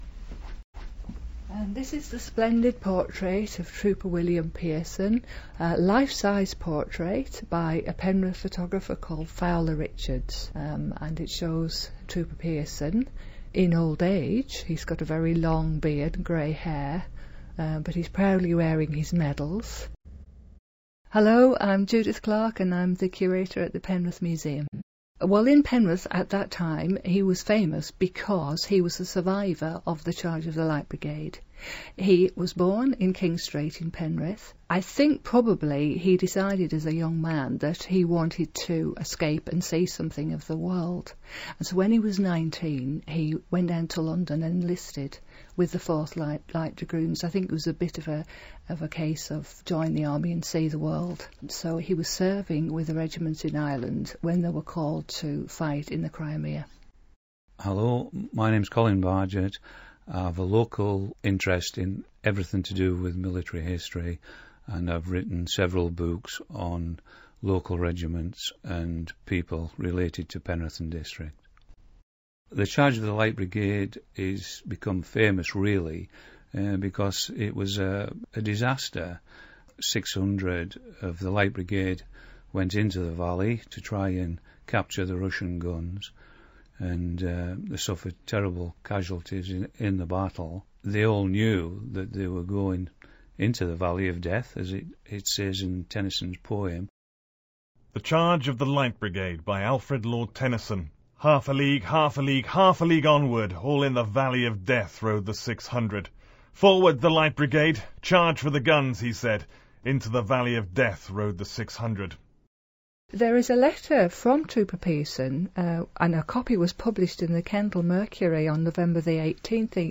and others talk about Trooper Pearson: